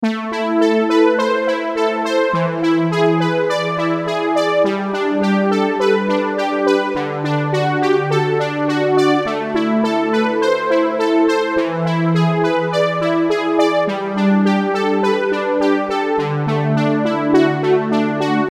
Smooth Analog Synth Loop
Download a smooth analog synth loop with warm, retro-inspired melodies and rich analog textures.
Genres: Synth Loops
Tempo: 104 bpm
Smooth-analog-synth-loop.mp3